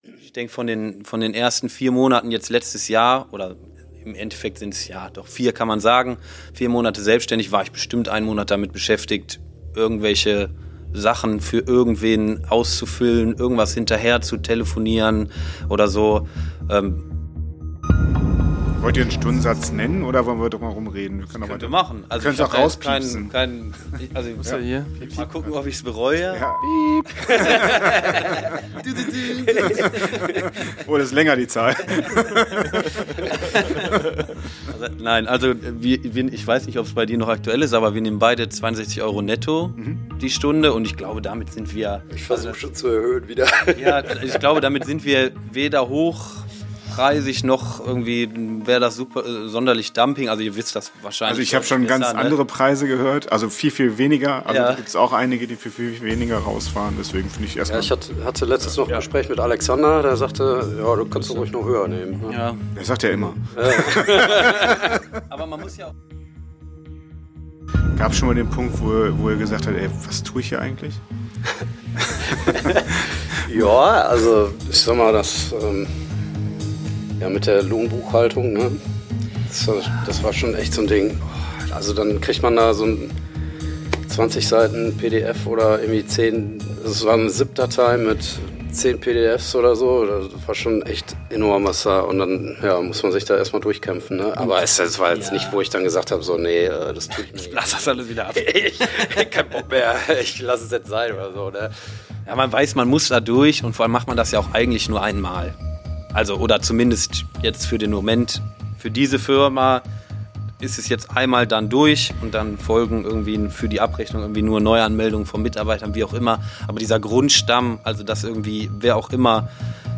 Wir 2 haben uns sich diesmal mit 2 Existenzgründern getroffen.